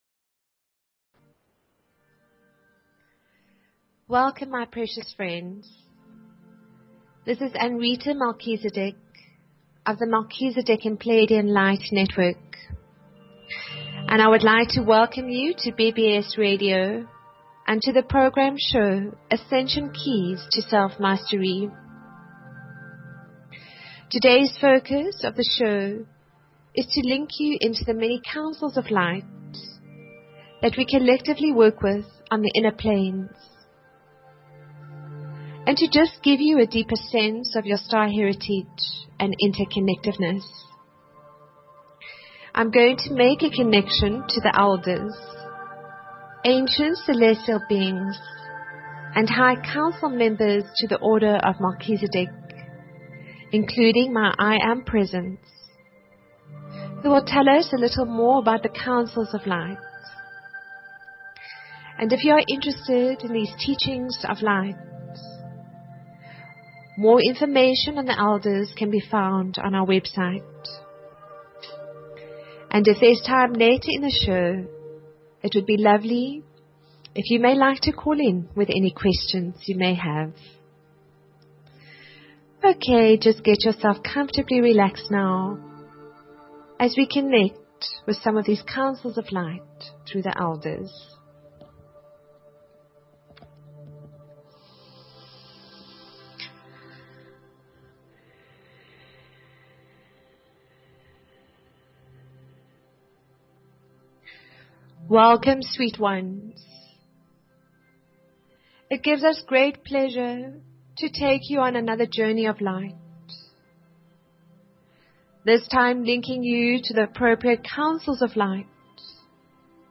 Talk Show Episode, Audio Podcast, Ascension_Keys_to_Self_Mastery and Courtesy of BBS Radio on , show guests , about , categorized as